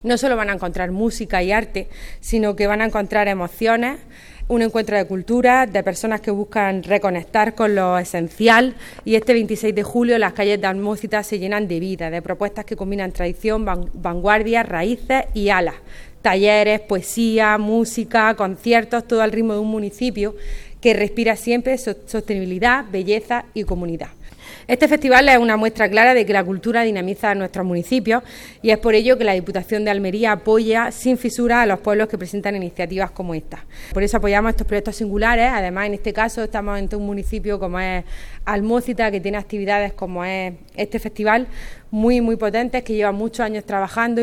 La Diputación de Almería y el Ayuntamiento de Almócita han presentado esta mañana en el Patio del Mandarino del área de Cultura de la Institución Provincial una nueva edición del Festival de arte ‘Alma de Almócita’, que se celebra este sábado 26 de julio.